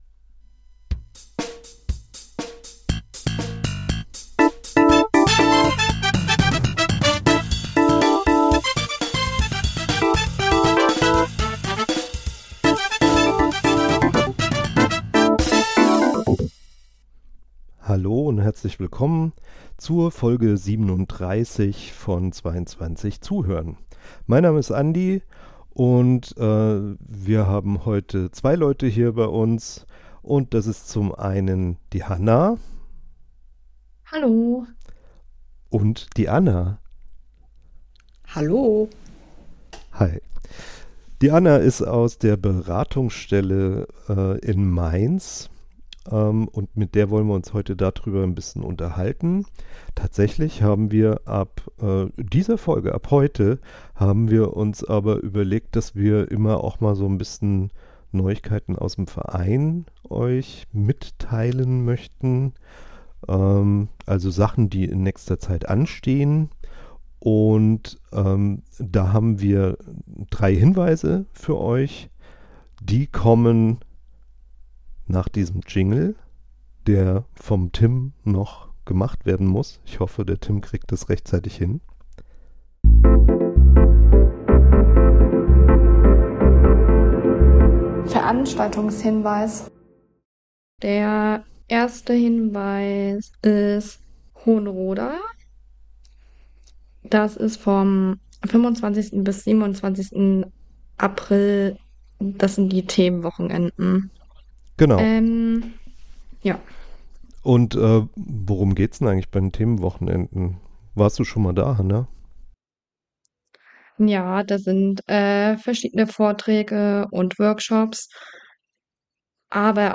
Wir haben uns mit einer Mitarbeiterin unterhalten